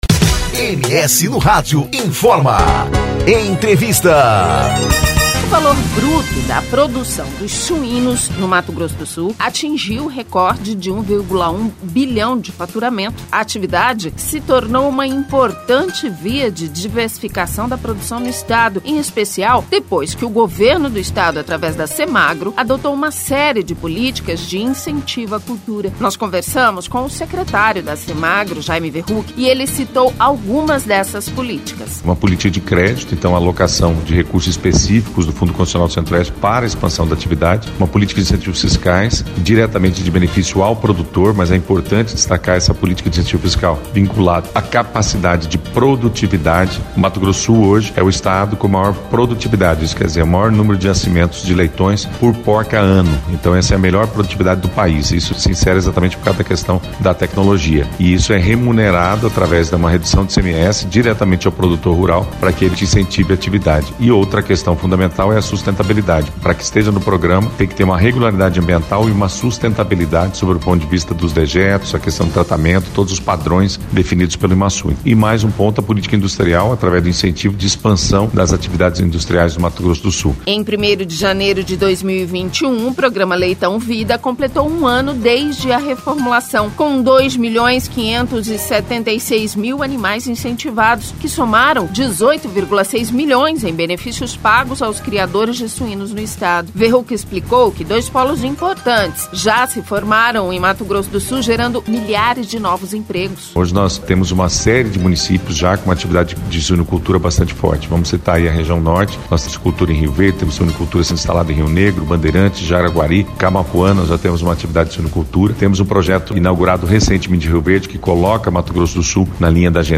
Nós conversamos com o secretário da Semagro, Jaime Verruck e ele citou algumas dessas políticas.